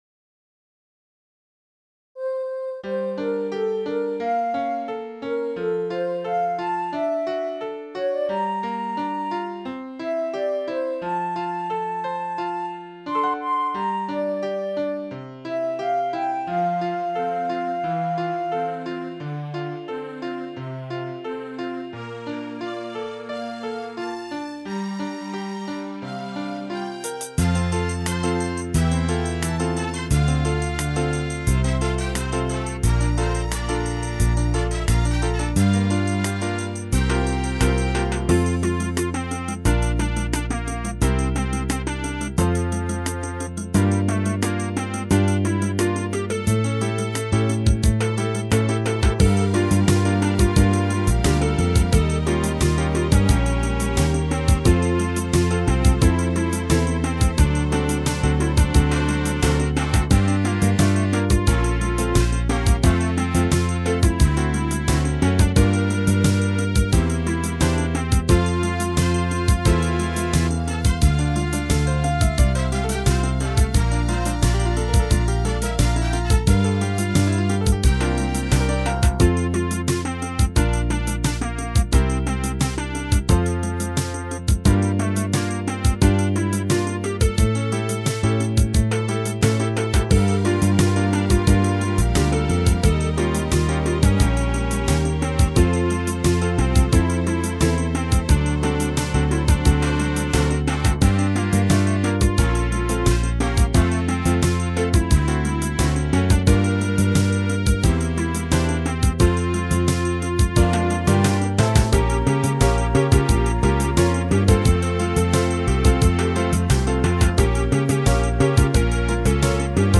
キューバン系ラテンバンド。そのあたりを意識してみた。